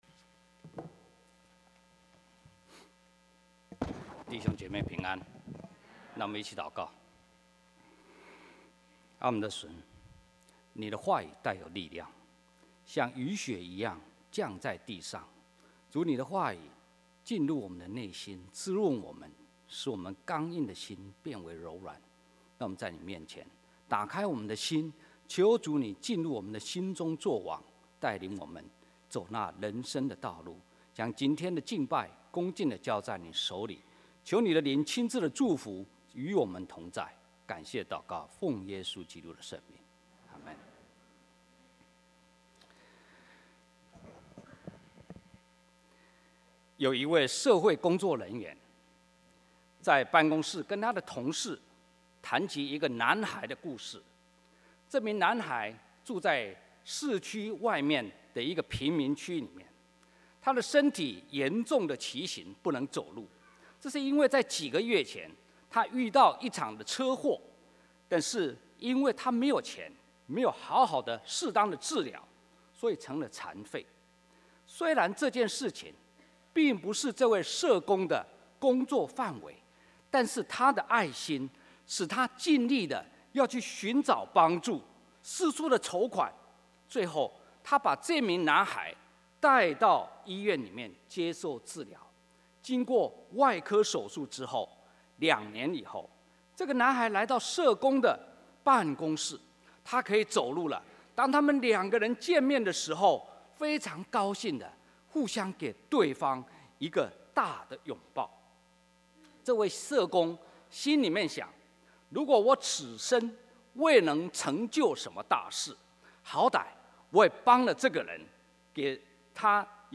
2011年主日講道錄音